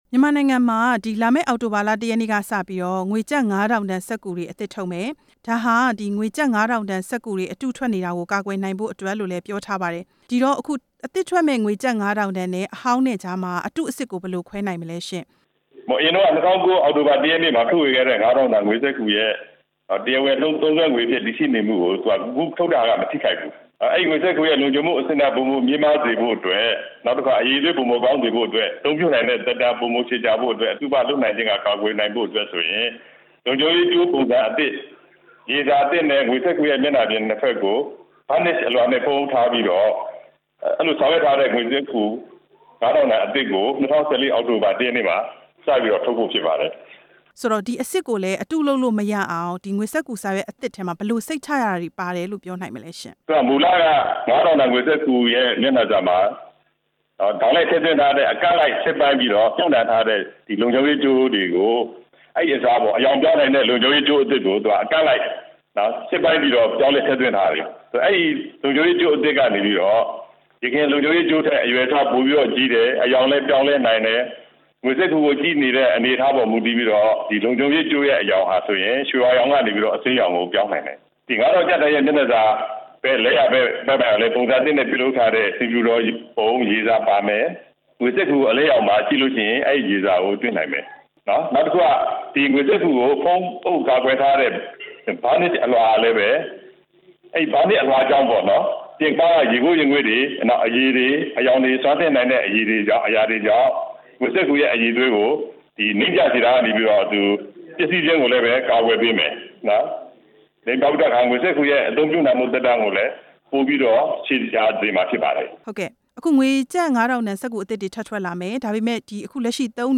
ကျပ် ၅၀၀၀ တန်သစ် ထုတ်ဝေရေးအစီအစဉ် ဆက်သွယ်မေးမြန်းချက်